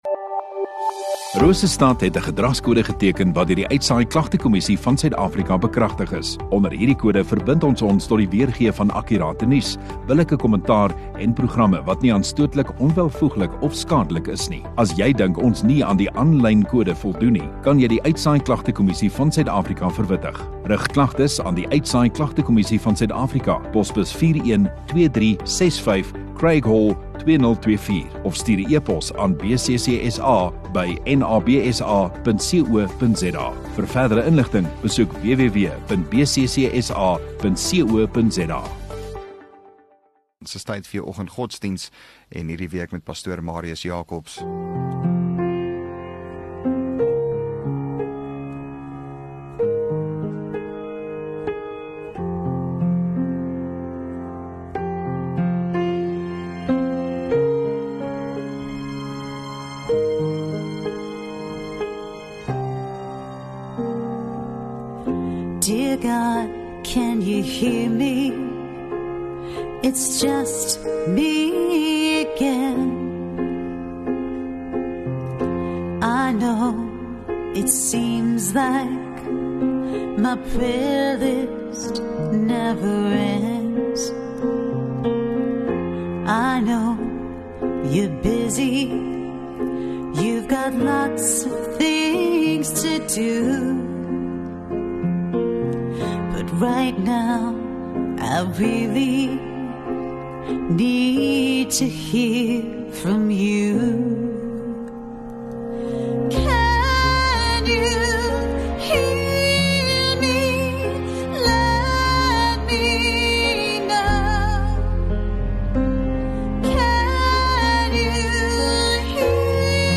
24 Feb Maandag Oggenddiens